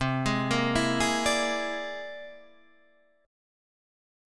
C+9 Chord
Listen to C+9 strummed